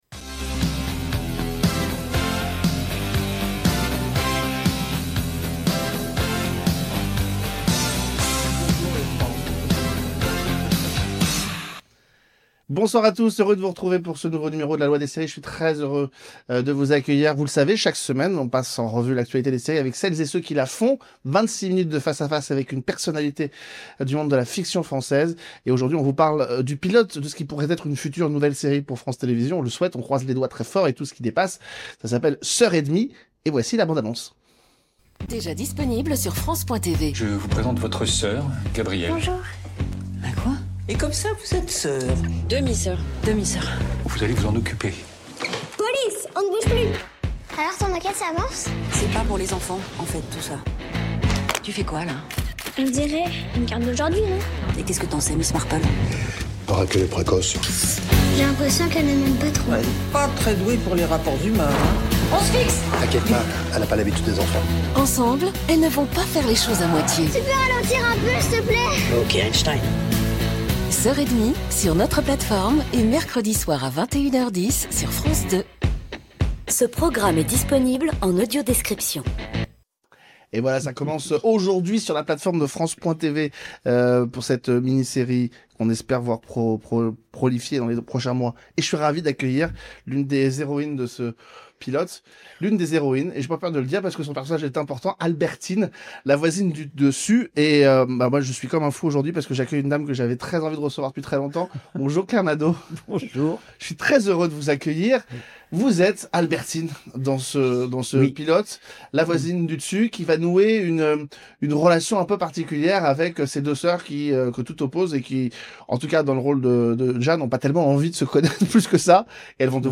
L’invitée : Claire Nadeau